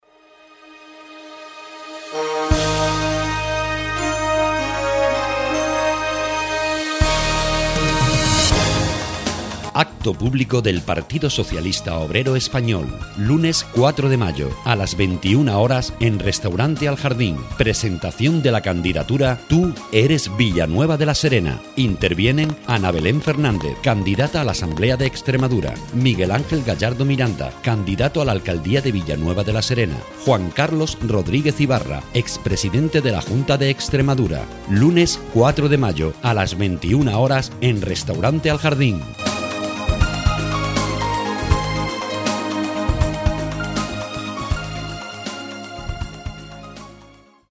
Acto público presentación de candidatura